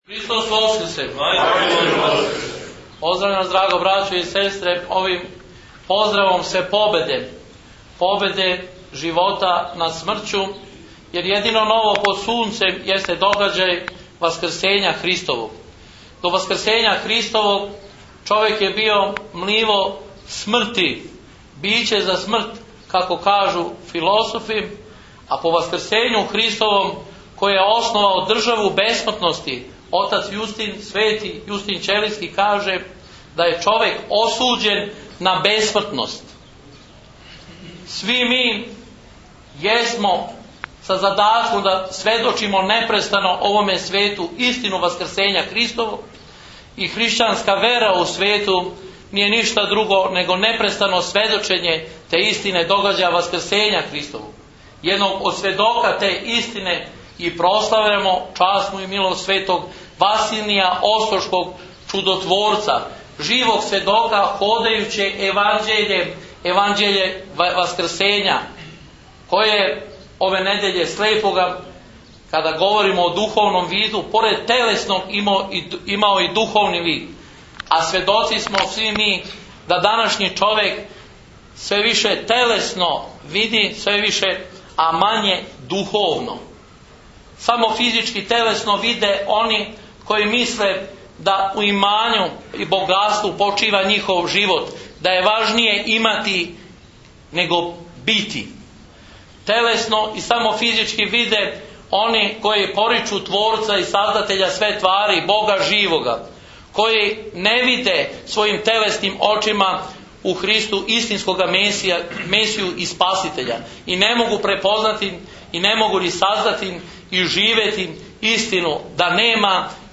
Прослава храмовне славе у Бачком Добром Пољу
Звучни запис беседе